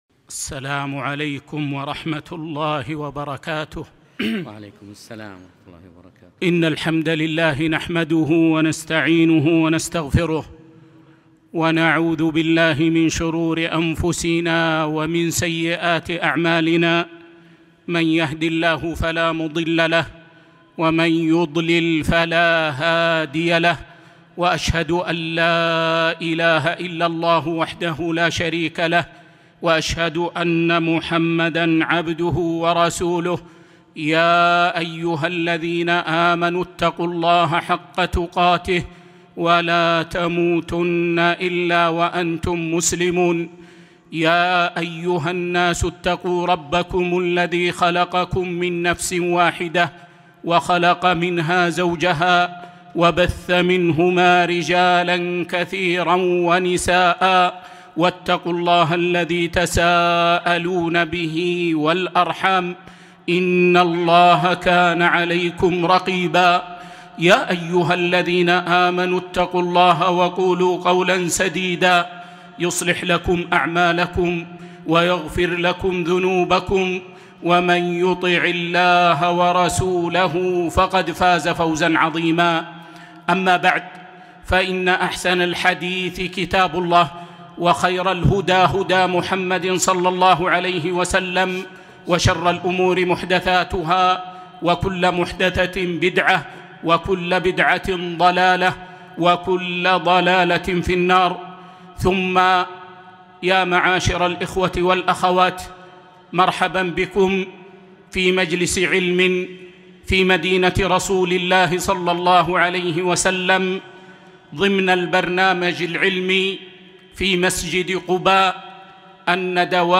محاضرة مؤثرة - رحلة الإنسان 4-7-1443